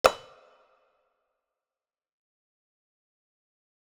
UI sound trad hover 2.wav